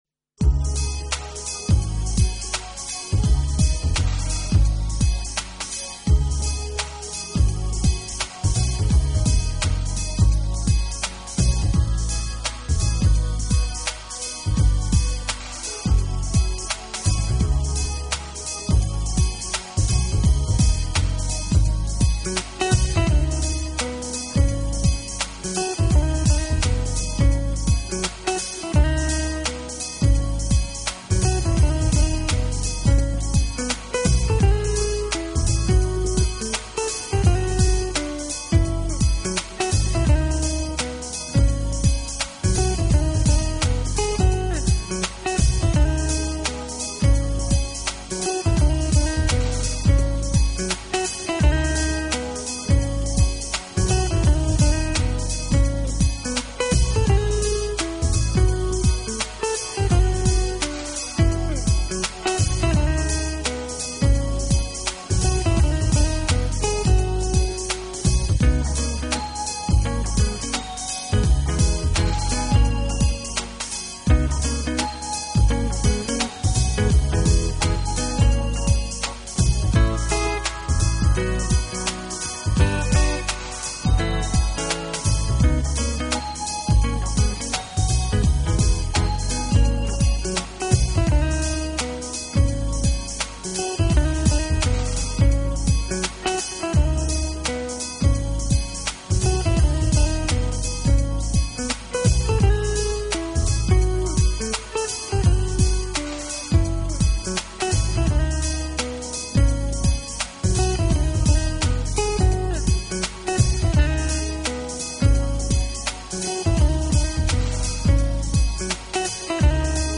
音乐类型：Acid Jazz（酸爵士）
Acid Jazz的最大特点就是重复的节拍和模式化的和声。